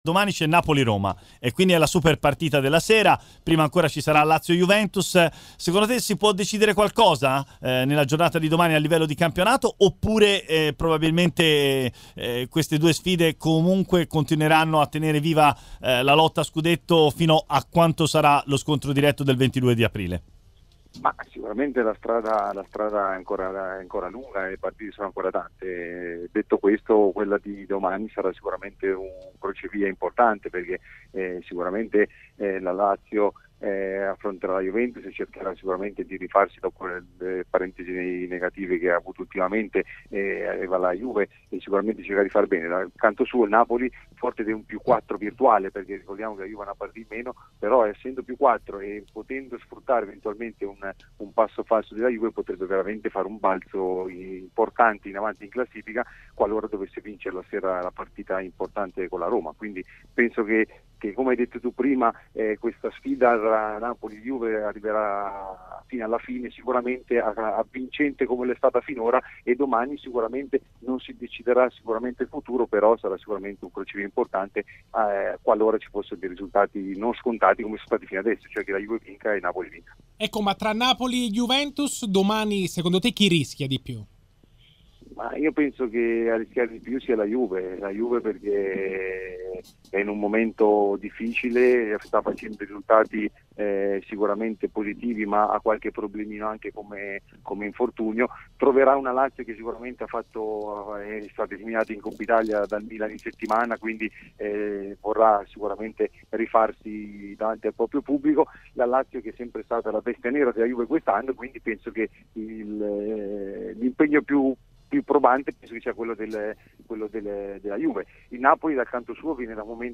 © registrazione di TMW Radio